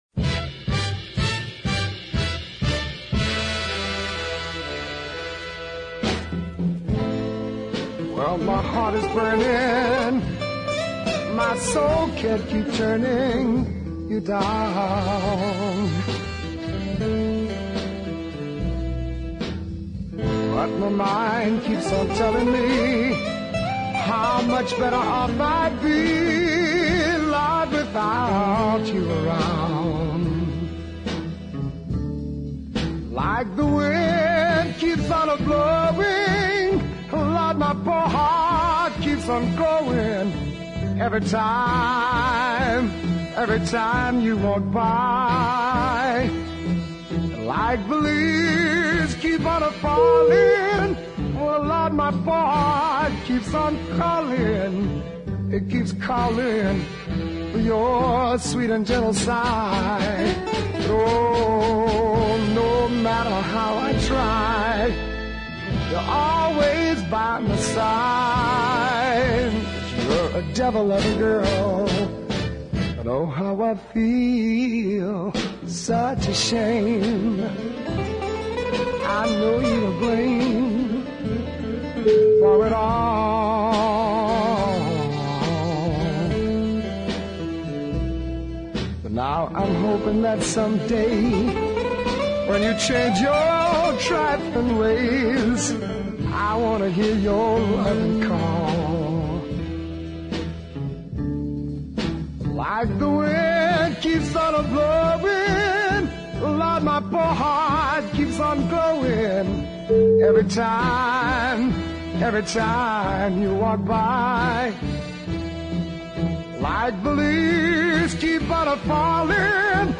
a wonderful blues ballad